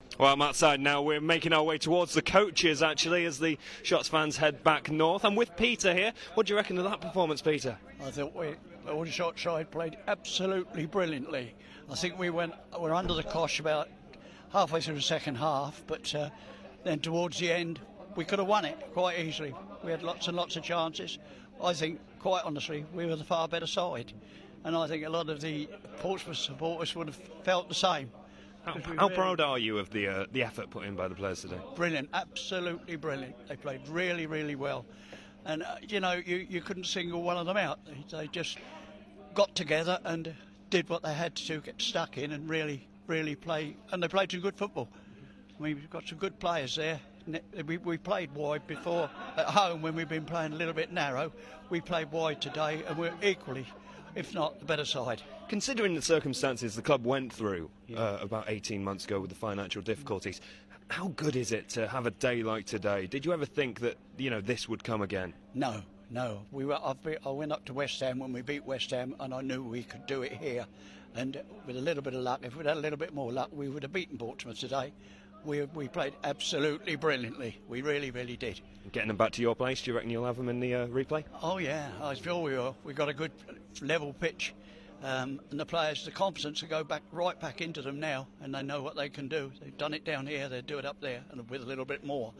Aldershot Town fan speaking